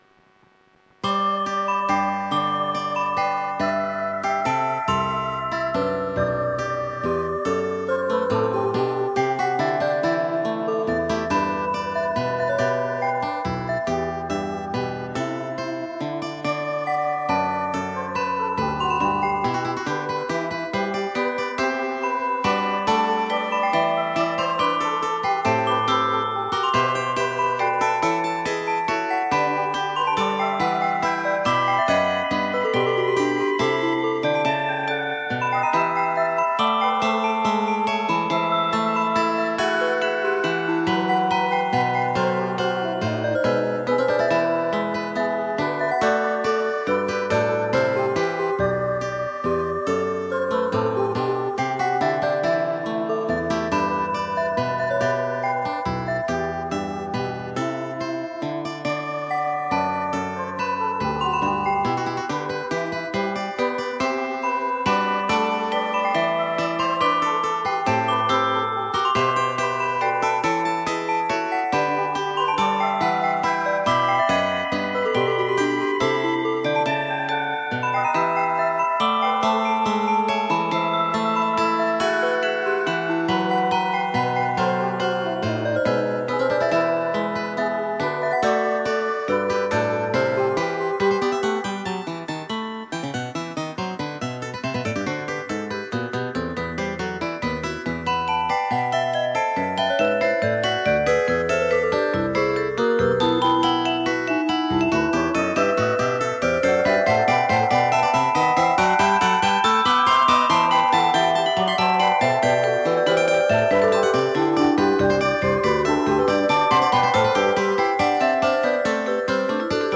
SACRED MUSIC ; POLYPHONIC MUSIC